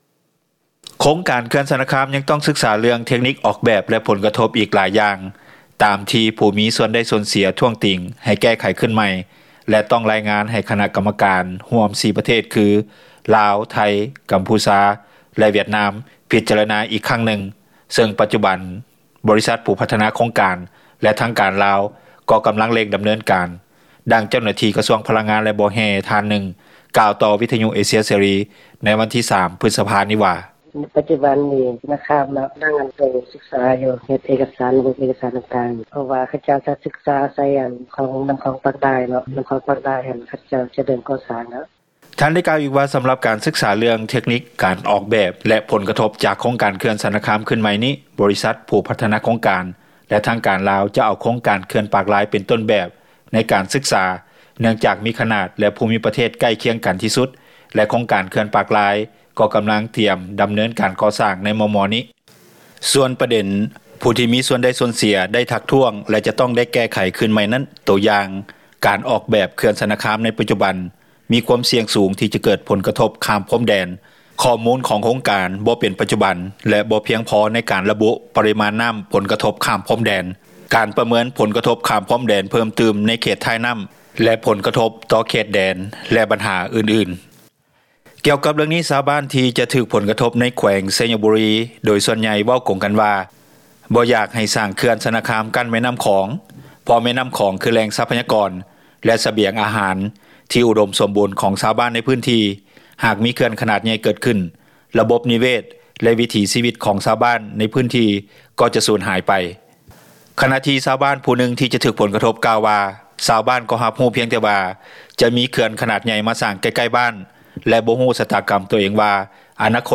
ດັ່ງເຈົ້າໜ້າທີ່ ກະຊວງພລັງງານ ແລະບໍ່ແຮ່ທ່ານນຶ່ງກ່າວ ຕໍ່ວິທຍຸເອເຊັຽເສຣີໃນວັນທີ 03 ພຶສພາ ນີ້ວ່າ:
ດັ່ງຊາວບ້ານຜູ້ນຶ່ງ ໃນເມືອງຊະນະຄາມ ແຂວງວຽງຈັນ ກ່າວຕໍ່ວິທຍຸເອເຊັຽເສຣີ ໃນວັນທີ 03 ພຶສພາ ນີ້ວ່າ:
ດັ່ງຊາວບ້ານຜູ້ນຶ່ງ ໃນເມືອງແກ່ນທ້າວ ແຂວງໄຊຍະບູຣີ ກ່າວຕໍ່ວິທຍຸເອເຊັຽເສຣີ ໃນວັນທີ 03 ພຶສພານີ້ວ່າ: